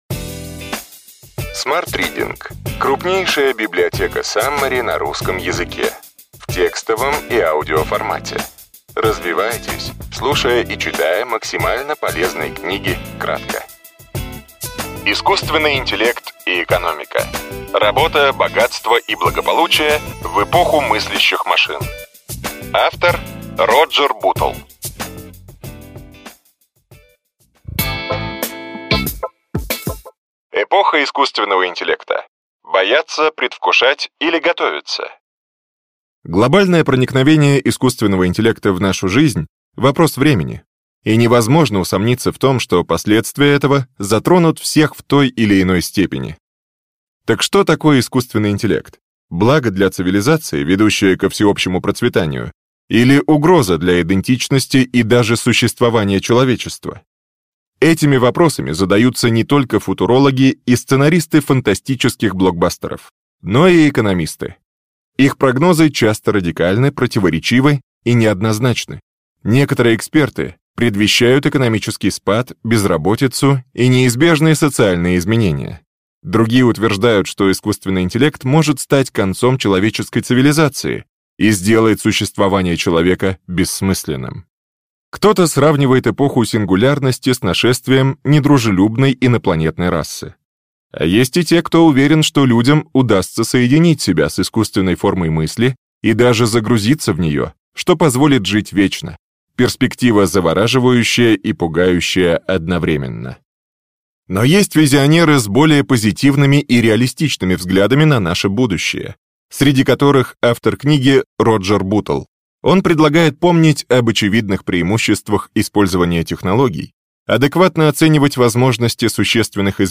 Аудиокнига Ключевые идеи книги: Искусственный интеллект и экономика. Работа, богатство и благополучие в эпоху мыслящих машин.